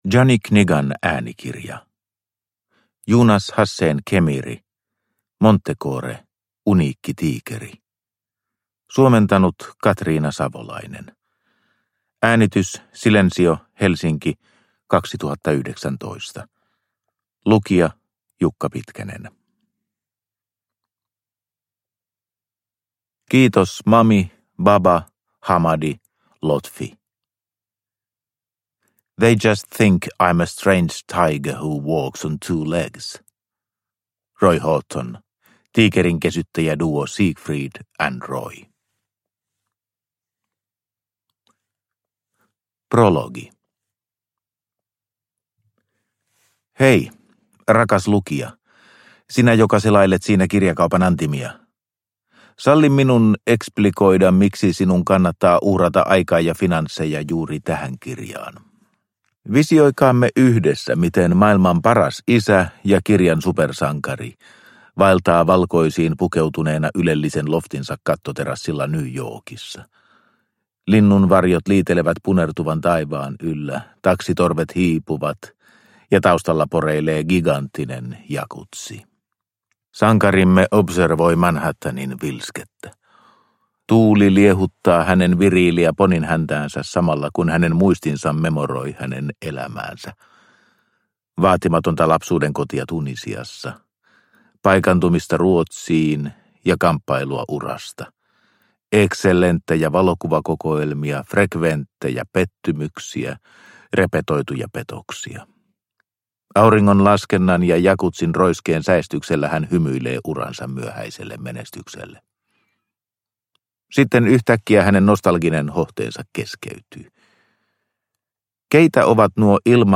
Montecore – Ljudbok – Laddas ner